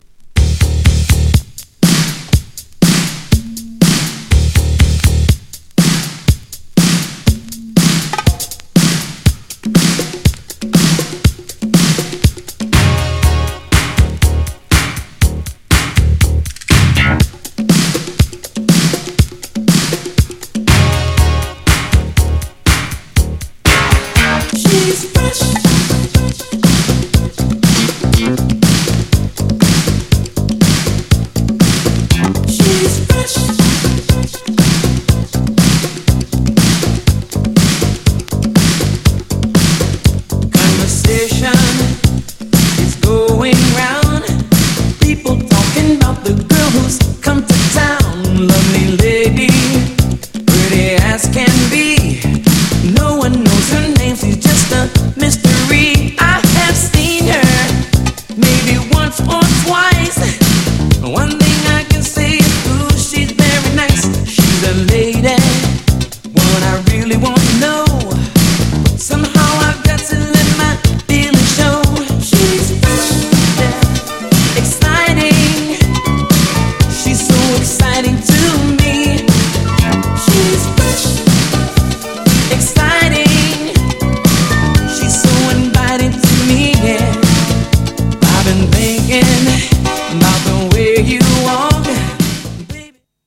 80'sなPOPサウンドのA面も良いが、
日本でもヒットしたB1に、スウィート＆メロウなB2!!
GENRE Dance Classic
BPM 91〜95BPM